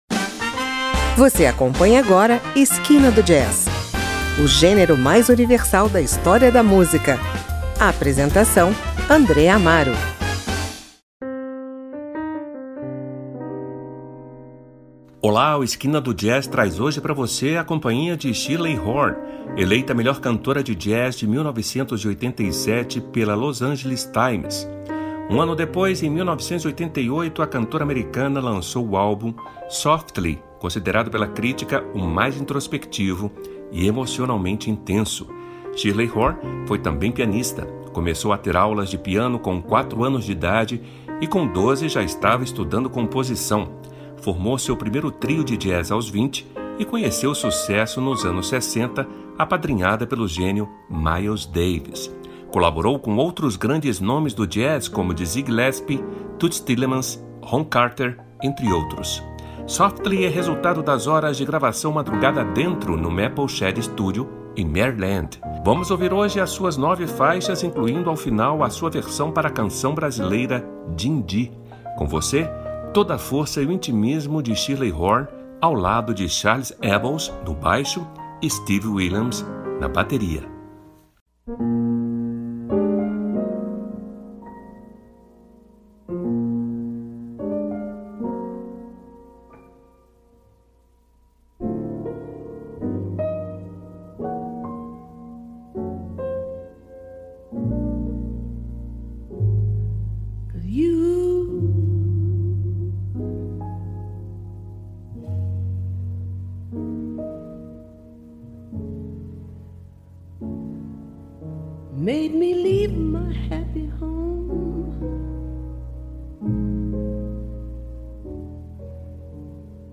no baixo
na bateria.